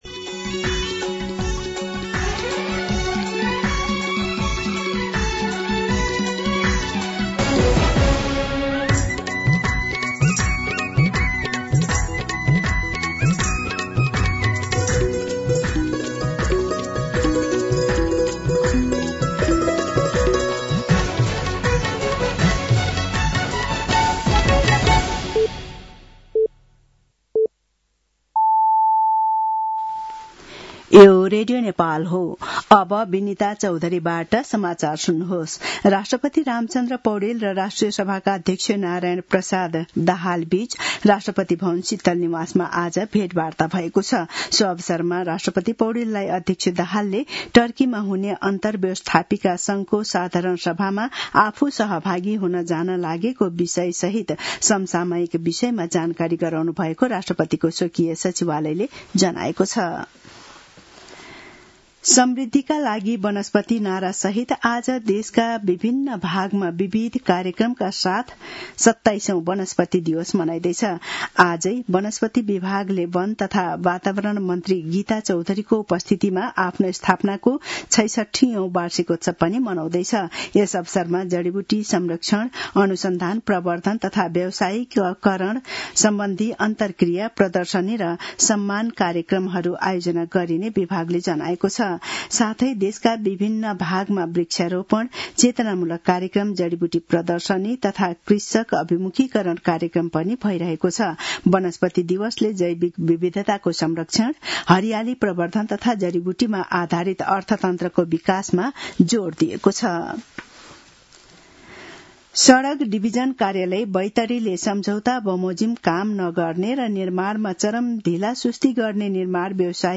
दिउँसो १ बजेको नेपाली समाचार : २९ चैत , २०८२